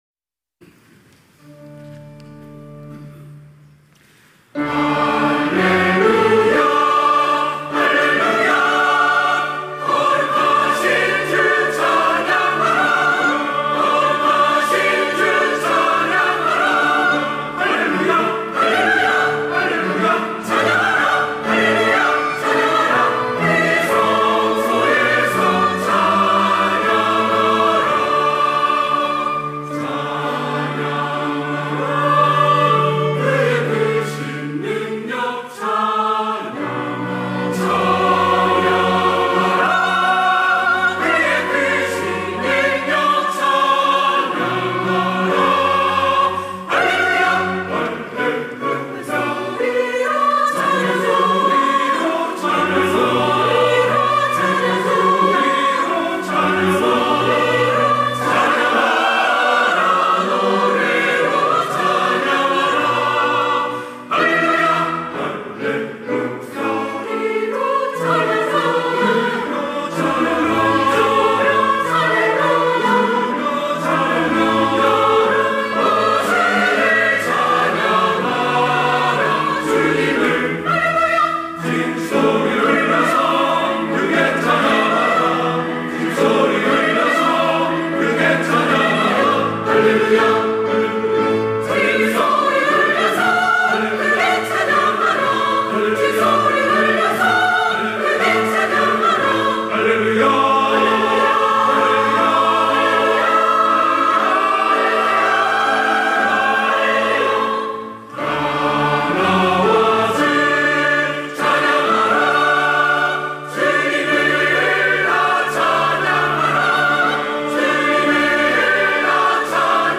호산나(주일3부) - 시편 150편
찬양대